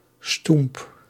Pronunciation
Stoemp is pronounced [stump]
Nl-stoemp.ogg.mp3